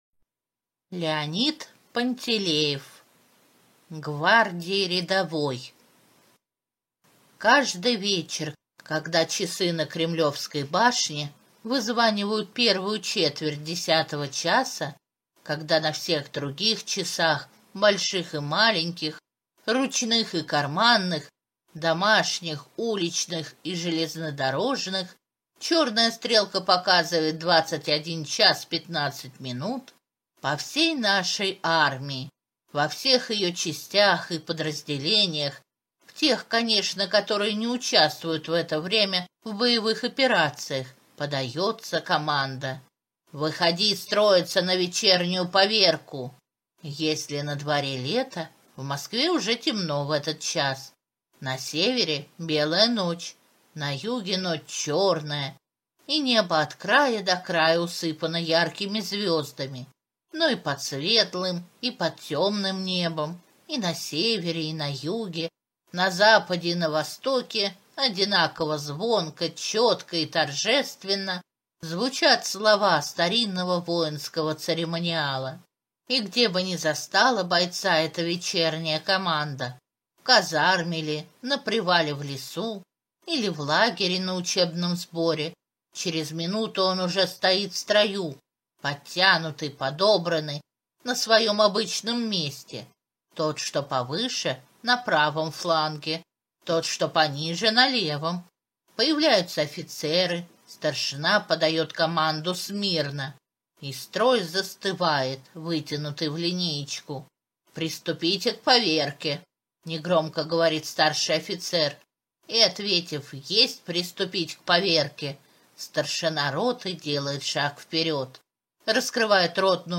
Гвардии рядовой - аудио рассказ Пантелеева - слушать онлайн